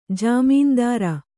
♪ jāmīndāra